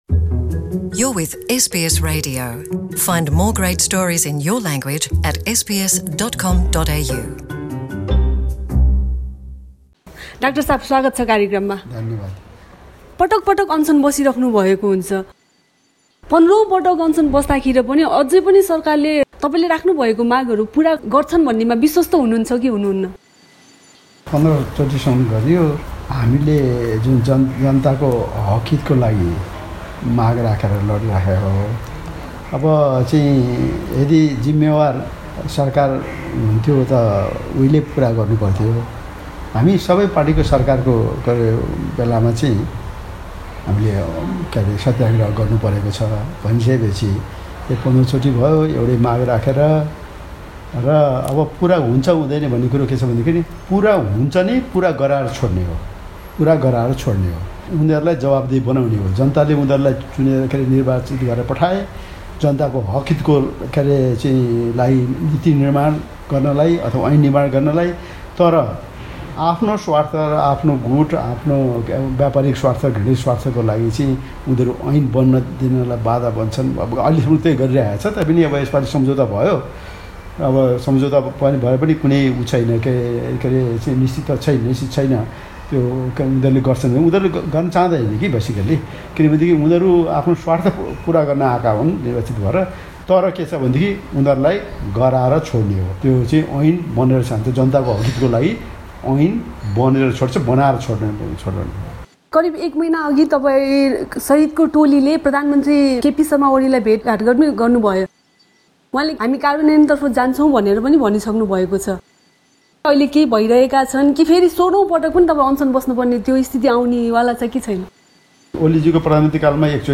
चिकित्सा शिक्षा सुधारको माग राख्दै डा. गोविन्द केसीले १५ औं पटक अनशन बस्दापनि प्रधानमन्त्री केपी शर्मा ओलीको सरकारले उनले राखेको मागहरु पुरा गर्न ढिलासुस्ती गरिरहेको उनि बताउँछन् । के उनि १६ औं पटक आफ्नो अनशन सुरु गर्न बाध्य हुने छन् त? एसबीएस नेपालीसँगको कुराकानीको क्रममा डाक्टर केसीको भनाइ यस्तो थियो।